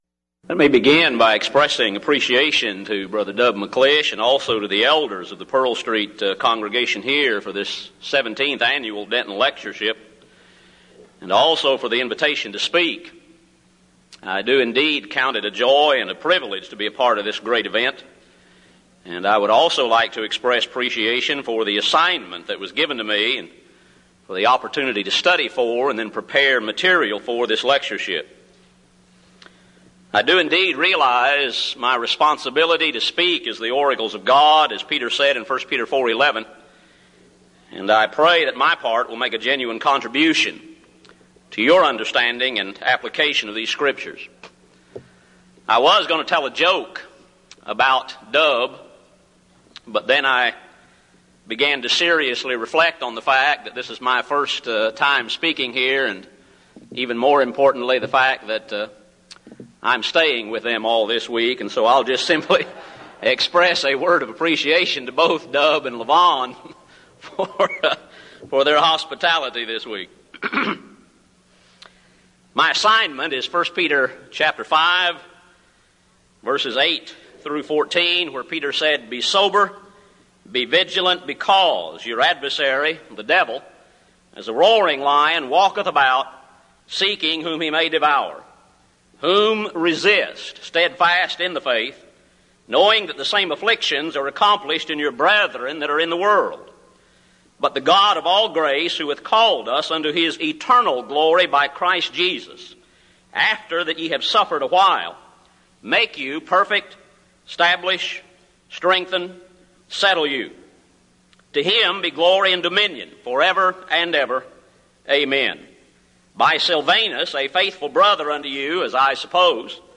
Event: 1998 Denton Lectures Theme/Title: Studies in the Books of I, II Peter and Jude
lecture